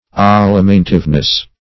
Meaning of alimentiveness. alimentiveness synonyms, pronunciation, spelling and more from Free Dictionary.
Search Result for " alimentiveness" : The Collaborative International Dictionary of English v.0.48: Alimentiveness \Al`i*men"tive*ness\, n. The instinct or faculty of appetite for food.